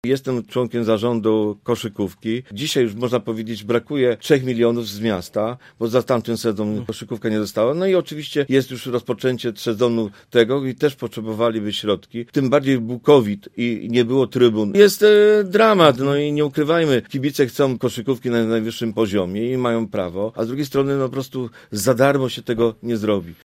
Jerzy Materna, poseł Prawa i Sprawiedliwości, który był dzisiaj gościem „Rozmowy po 9”, mówił, że o sporcie zawodowym należy rozmawiać, ale nie w taki sposób: